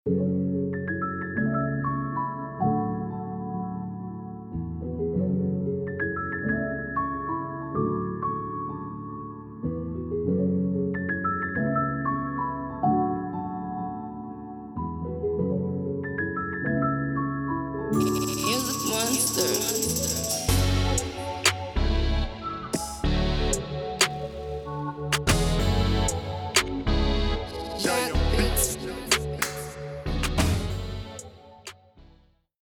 Tempo: 94
Genre: Afrobeats